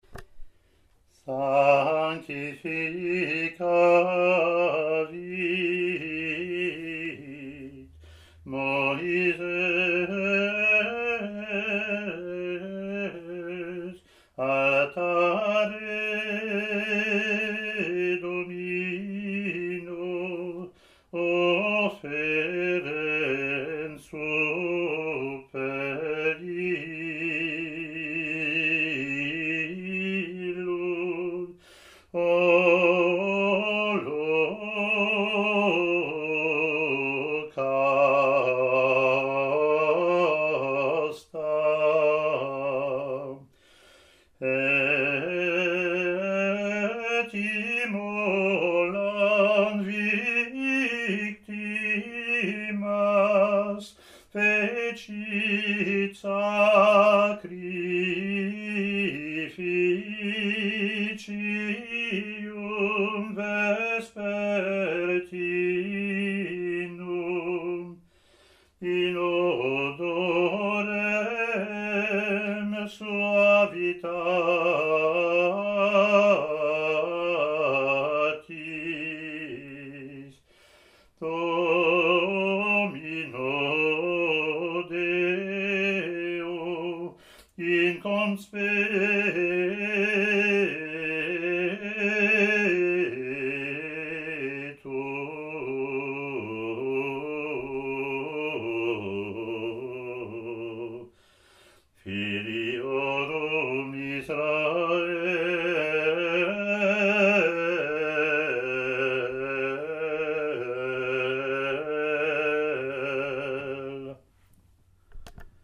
Latin antiphon)
ot24-offertory-gm.mp3